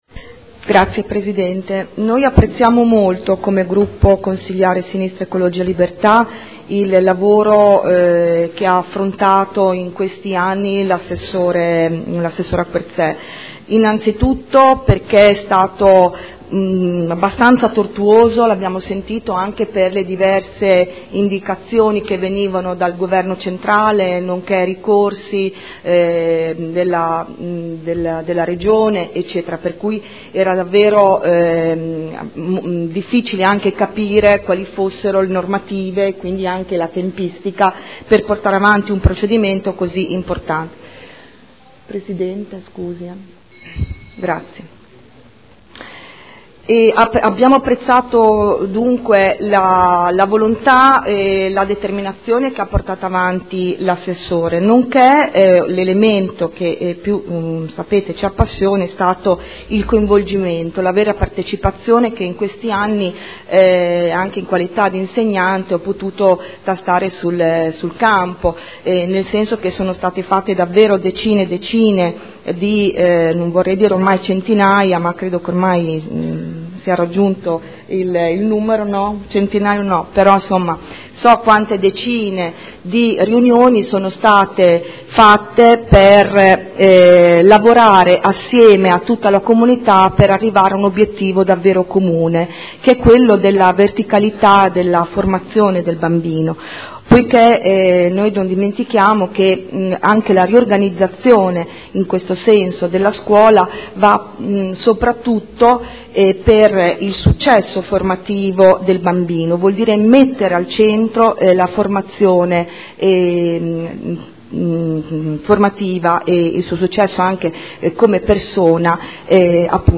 Seduta del 21/11/2013 Proposta di deliberazione: Modifica della riorganizzazione rete Istituzioni scolastiche approvata con deliberazione del Consiglio comunale n. 63/2012 Dibattito